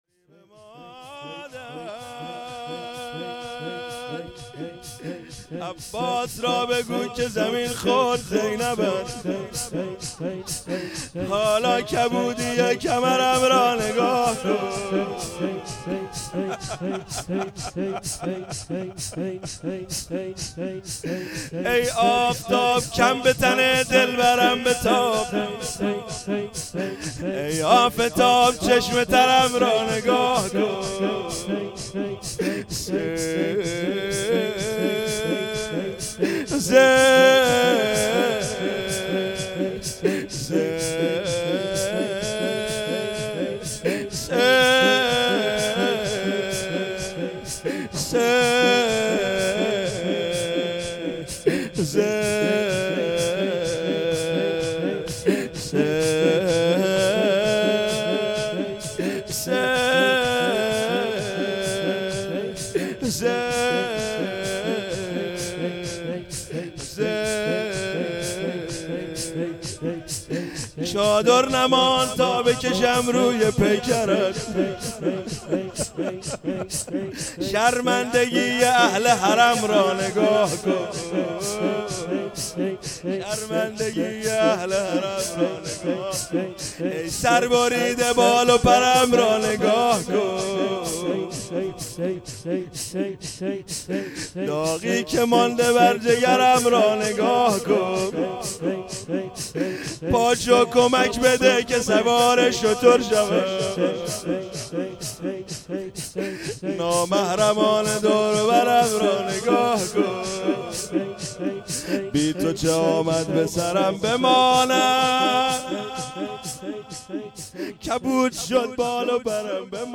شور | شب عاشورا محرم ۹۹
شب دهم محرم 99